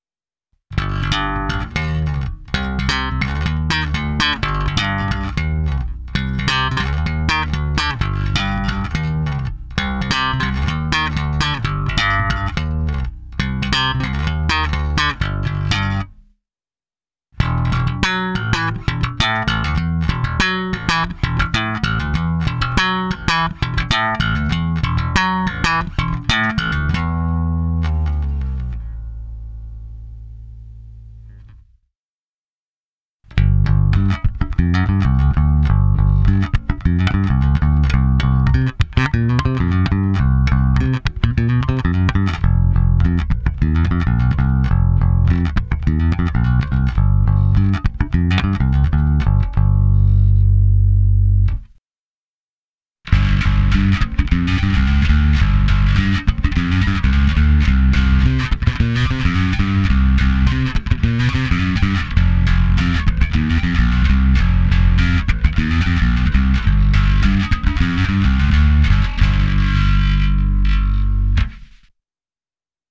H, E a A krásně zvoní, vrní, mají naprosto konkrétní zvuk, ale D a G jsou trochu pod dekou, nevím, co bylo důvodem to tak vyrábět.
Udělal jsem nahrávku, tím slapem jsem v ní začal, pak následuje ukázka prsty bez a se zkreslením. Nahrané je to na mou baskytaru Fender American Professional II Precision Bass V.
Zvuk, jak slyšíte, je hodně kovový.